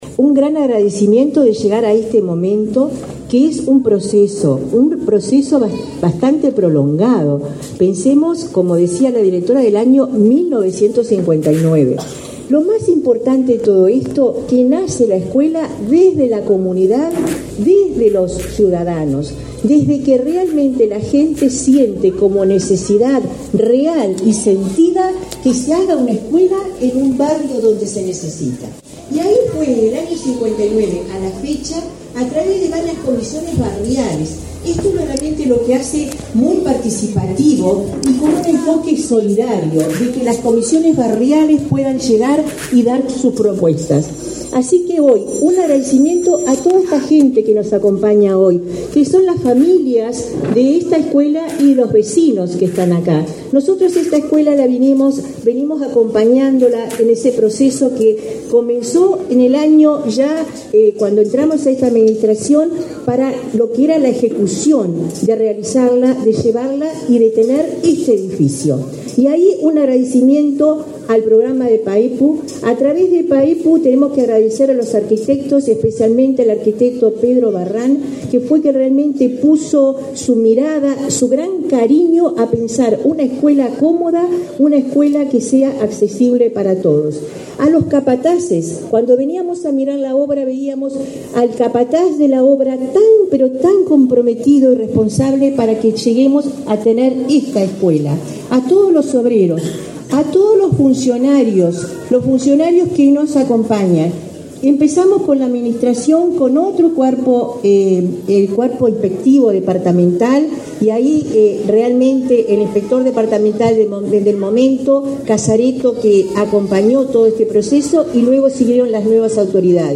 Conferencia de prensa por inauguración de escuela de tiempo completo en Montevideo
La Administración Nacional de Educación Pública (ANEP), a través de la Dirección General de Educación Inicial y Primaria, inauguró, este 23 de mayo, la escuela n.º 407, de tiempo completo, en Montevideo. En el acto participó el director de la ANEP, Robert Silva, y la subdirectora del organismo, Olga de las Heras.